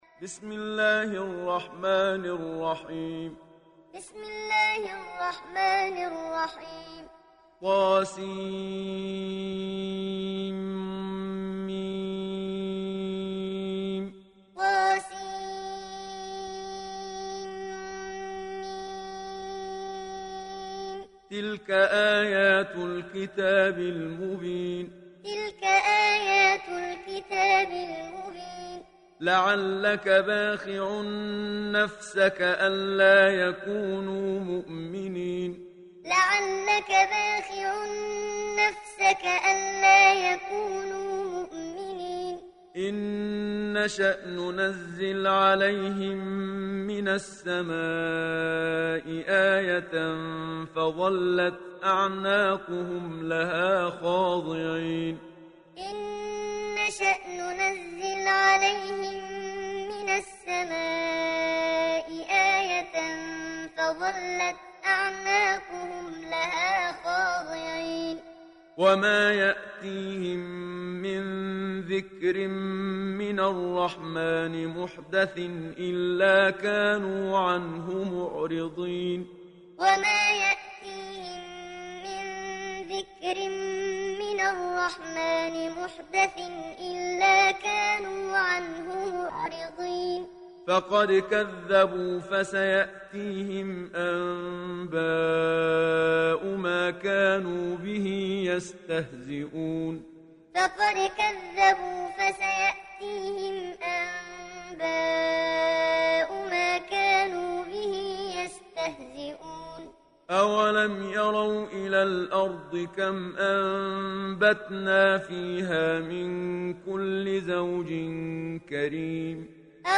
Şuara Suresi İndir mp3 Muhammad Siddiq Minshawi Muallim Riwayat Hafs an Asim, Kurani indirin ve mp3 tam doğrudan bağlantılar dinle
İndir Şuara Suresi Muhammad Siddiq Minshawi Muallim